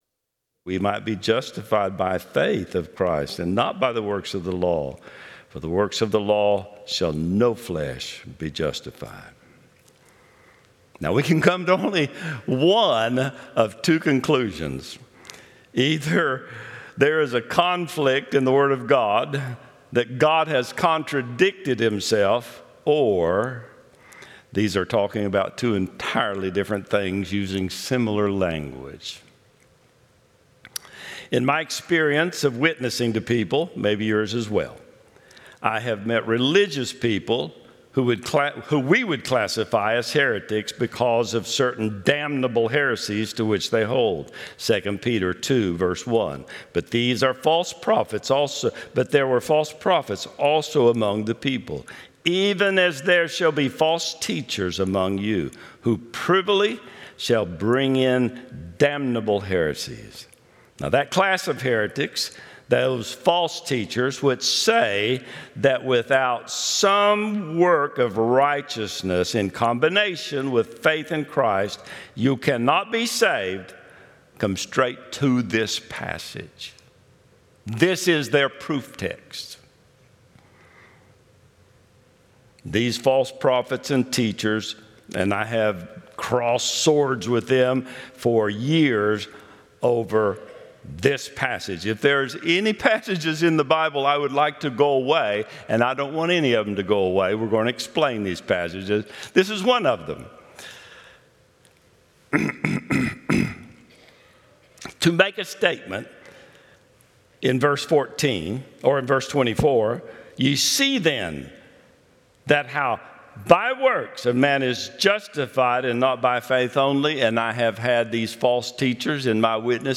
12-21-25 Sunday School | Buffalo Ridge Baptist Church